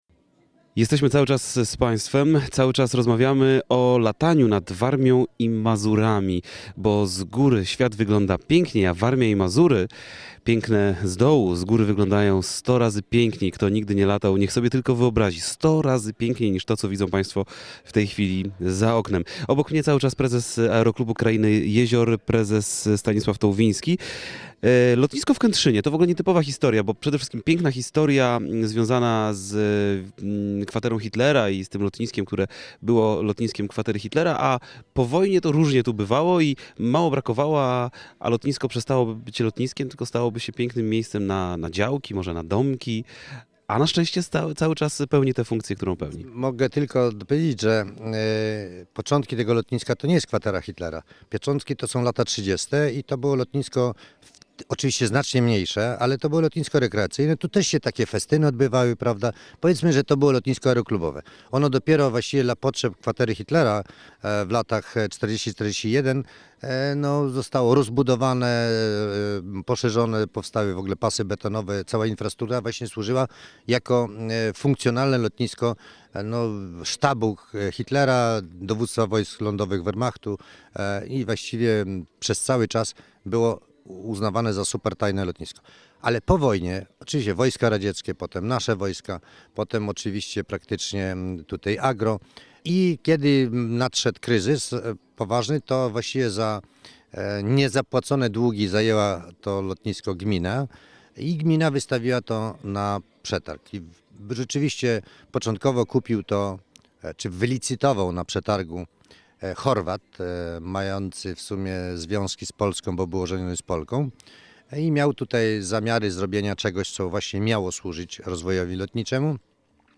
2009-06-13Relacja z Rajdu po Lotniskach Warmii i Mazur - Kętrzyn Wilamowo (źródło: Radio Olsztyn)